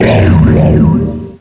pokeemerald / sound / direct_sound_samples / cries / cofagrigus.aif